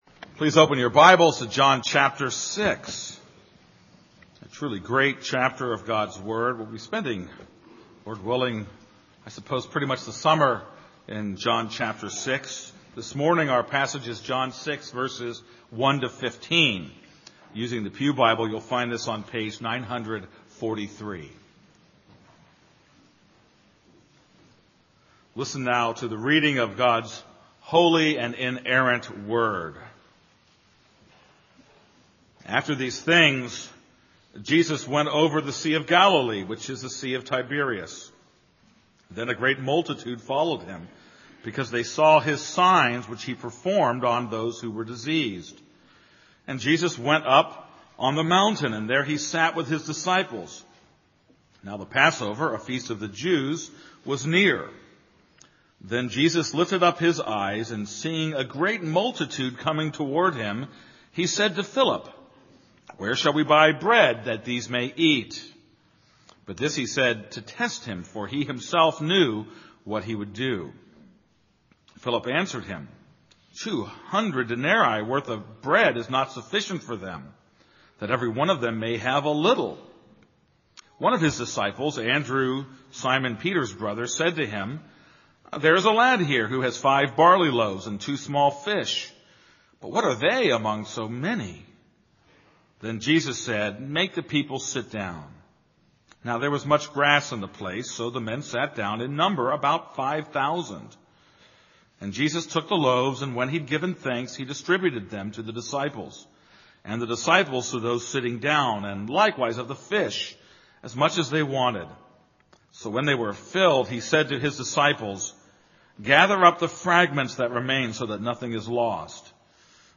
This is a sermon on John 6:1-15.